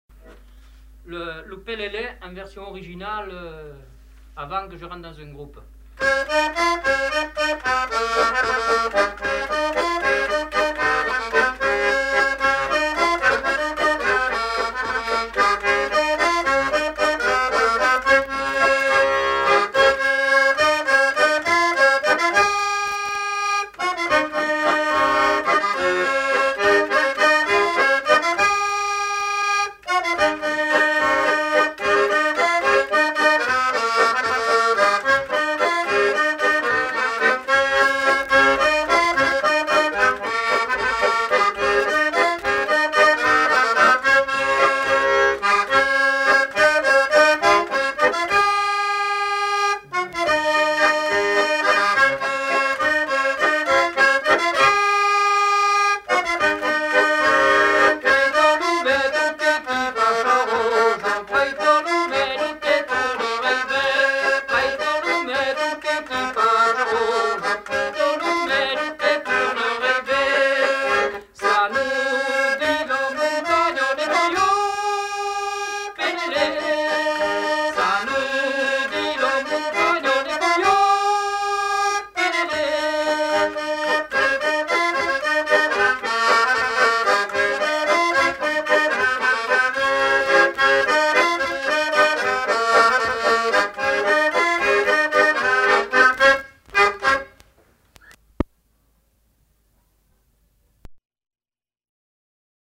Aire culturelle : Limousin
Lieu : Juillac
Genre : chanson-musique
Type de voix : voix d'homme
Production du son : chanté
Instrument de musique : accordéon chromatique
Danse : pélélé